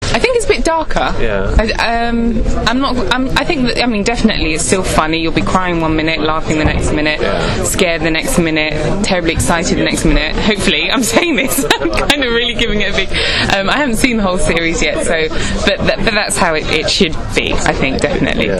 And here’s are a few short audio extracts from the interview with Lenora: